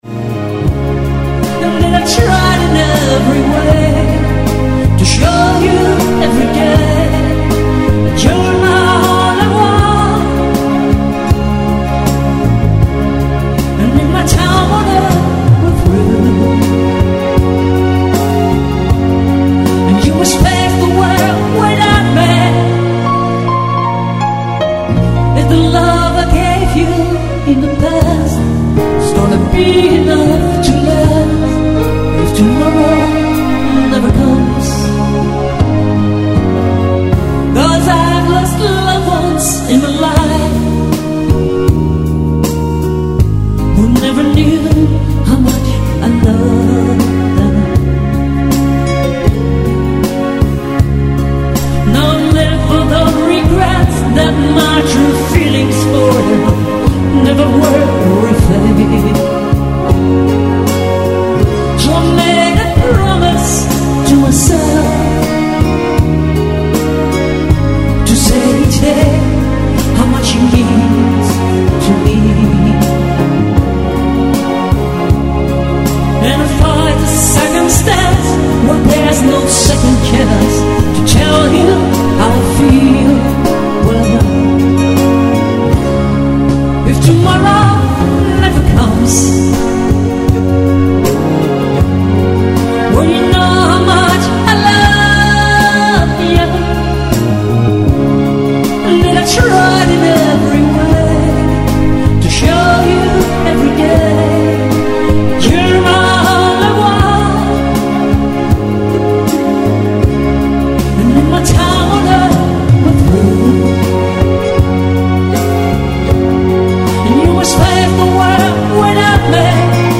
Synt-gesang-trompete-drums
Gitarre, percussion